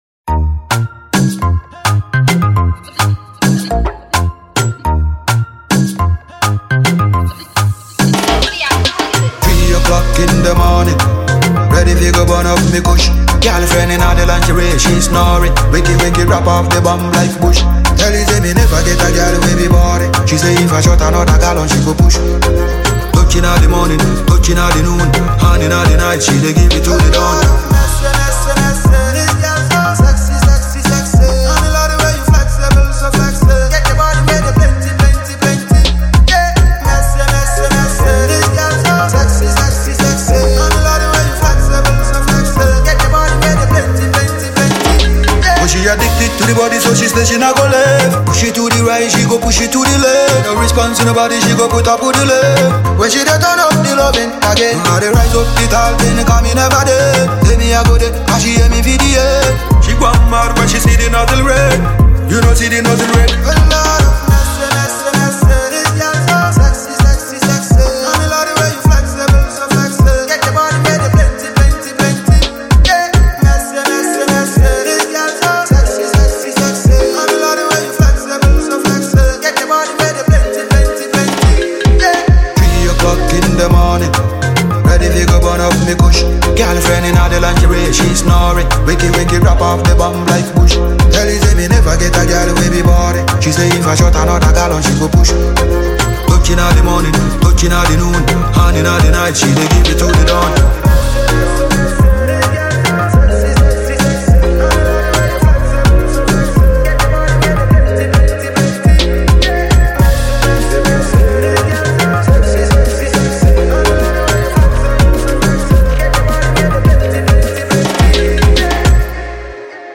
GHANA MUSIC
This is a dancehall musical series